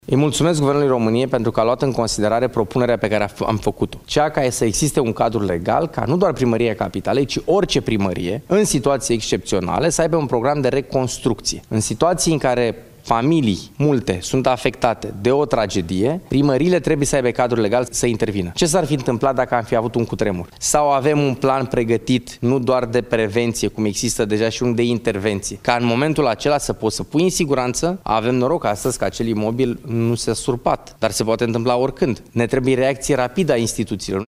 Primarul interimar al Capitalei a vorbit și despre reconstrucția blocului de către municipalitate.